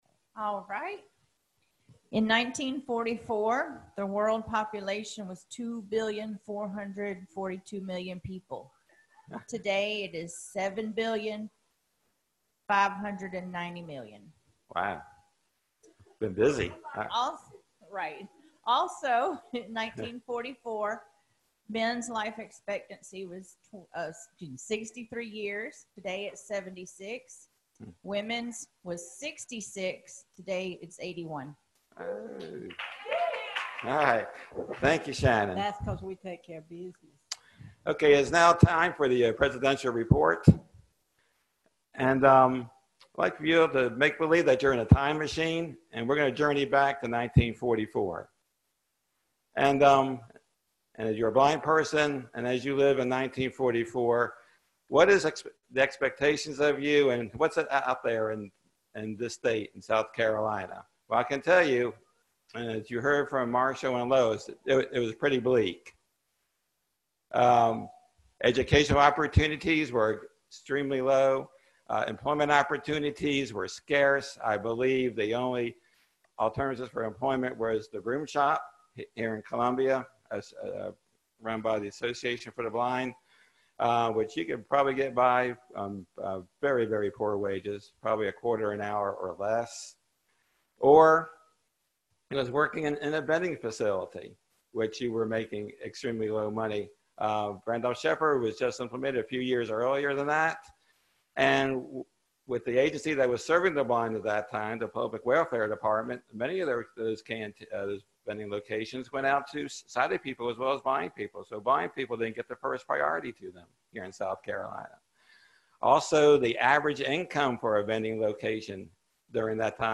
2019 State Convention | National Federation of the Blind of South Carolina